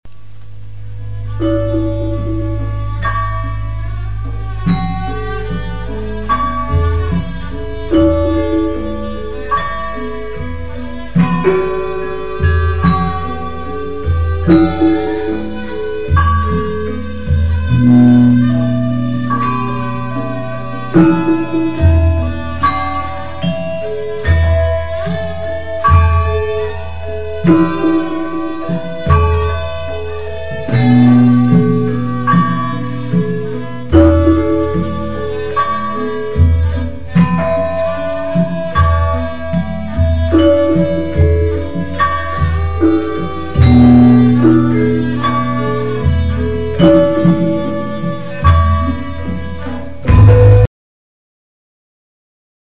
Javansk karawitan
Rebab.